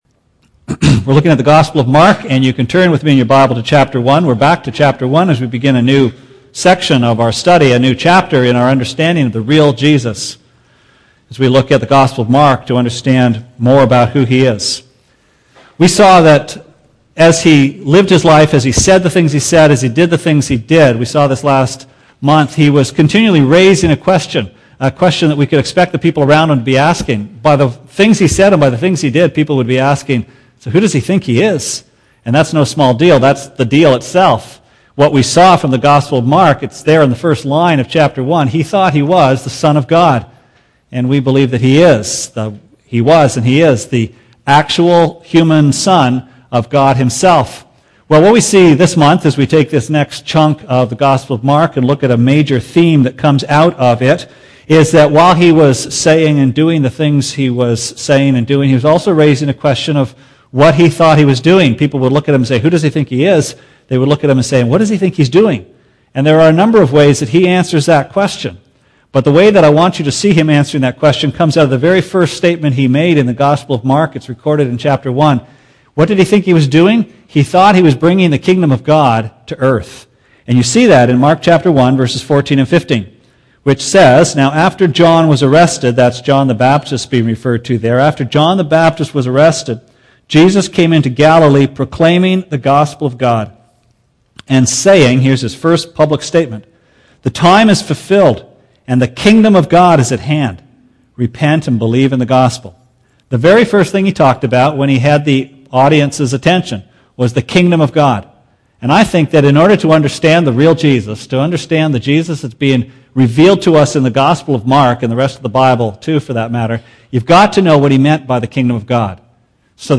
Sermon Archives March 1, 2009: The Secret Kingdom This morning we look at the first of four sermons from the Gospel of Mark on the kingdom of God.